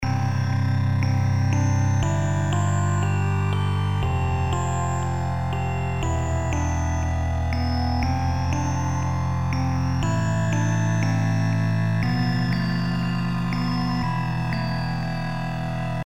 描述：合成器垫变化在其中...听
Tag: 130 bpm Pop Loops Pad Loops 3.59 MB wav Key : Unknown